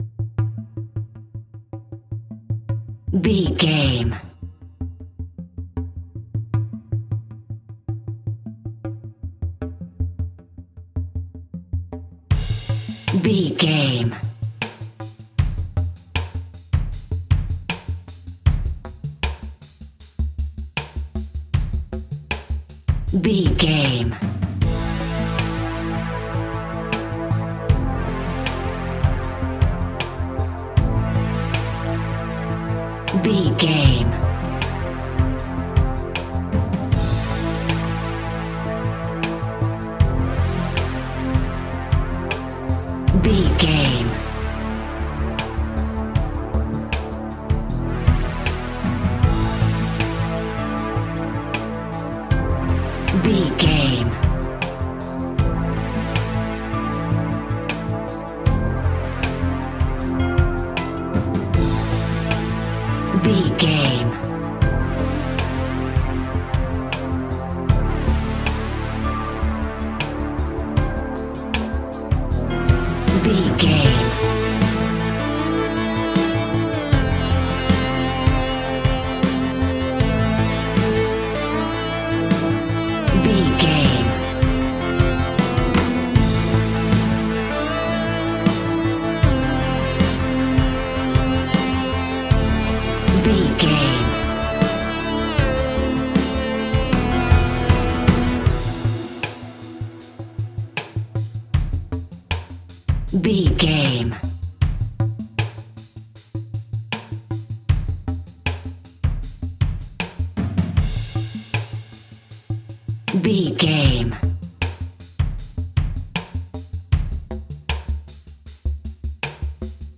Aeolian/Minor
dark
piano
synthesiser